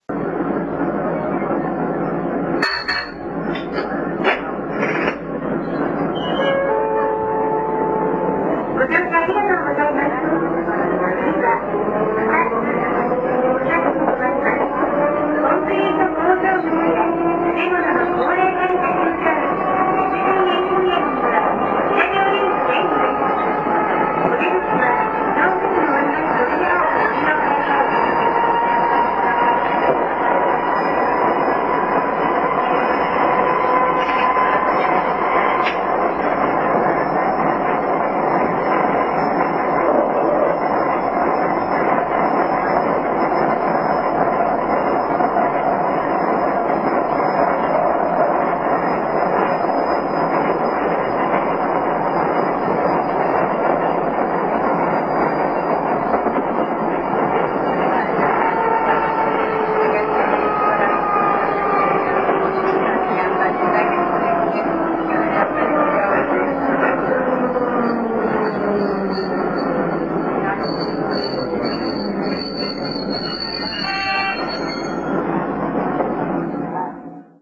■車内で聴ける音■